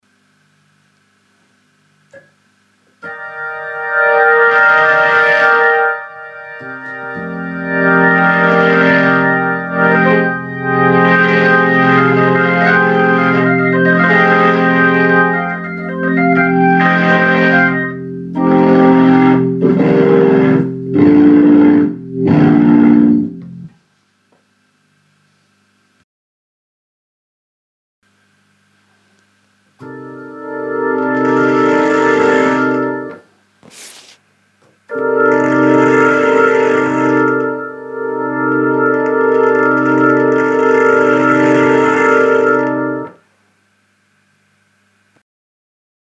Am Anfang hörte man den Leslie, voll aufgedreht im 122Mode und den Power Soak auf Bypass. Man hört eine starke Verzerrung, die aber so klingt, wie sie soll. Natürlich viel zu laut für das kleine Zimmer. Dann gibt es eine 2sekündige Pause Dann (ab etwa Sekunde 28) schalte ich den Power Break hoch auf ungefähr 50% und man hört, dass es statt der Verzerrung ein starkes Schnarren gibt.